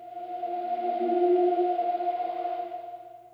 synthFX02.wav